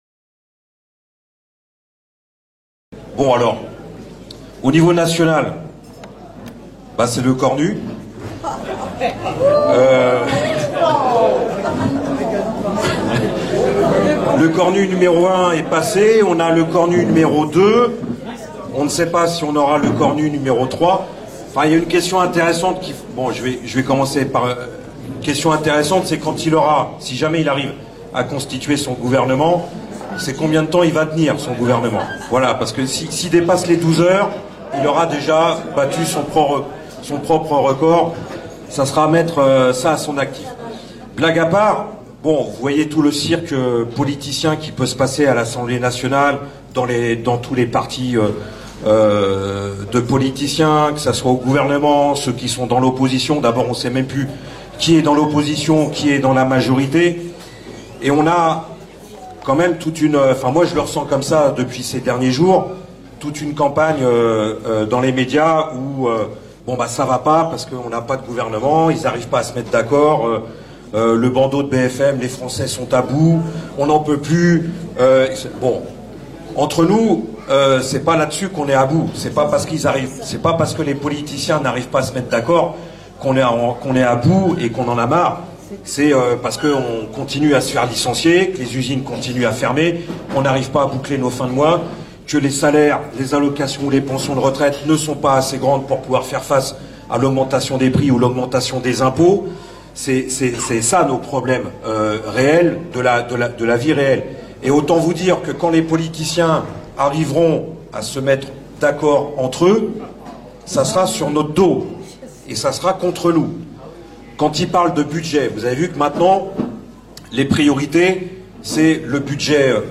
Débat
à la fête de Bordeaux